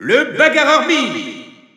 Announcer pronouncing Mii Brawler's name with masculine pronouns in French in victory screen.
Mii_Brawler_M_French_Alt_Announcer_SSBU.wav